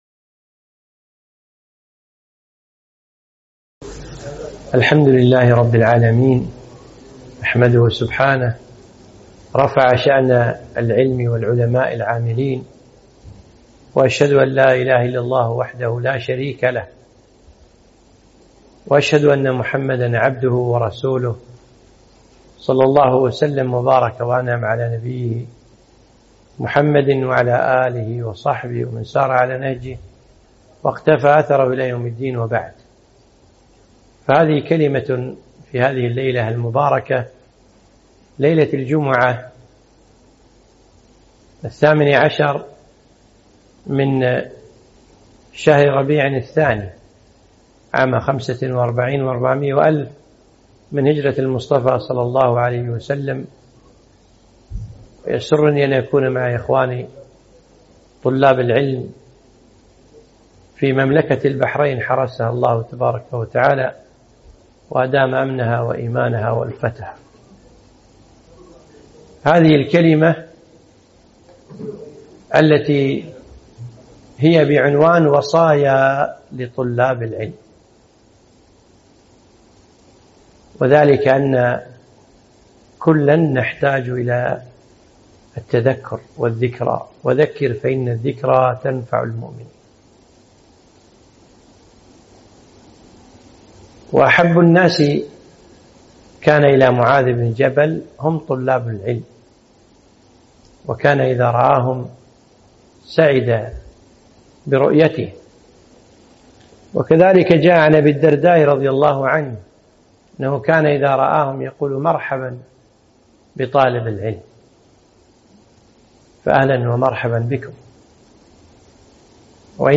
محاضرة - وصايا لطلاب العلم